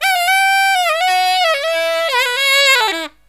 63SAXMD 03-L.wav